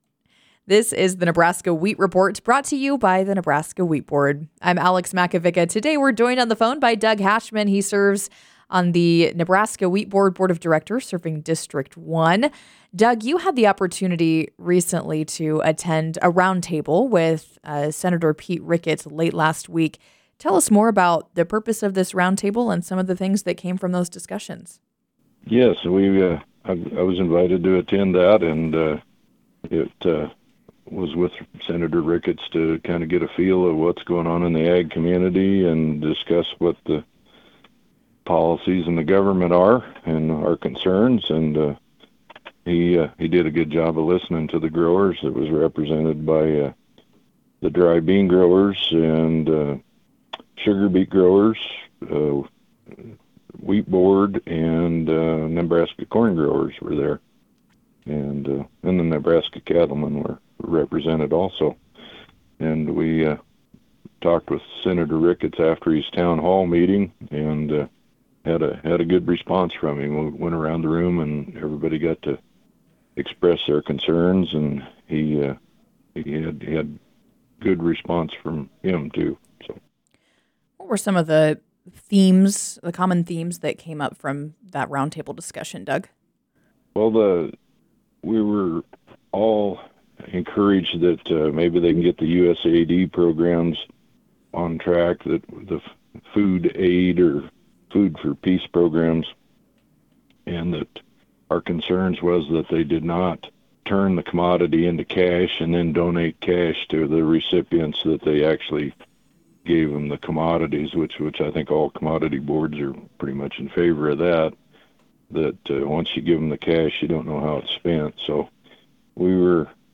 The Wheat Report are interviews conducted with farmers and wheat industry representatives regarding current events and issues pertaining to the Nebraska Wheat Board.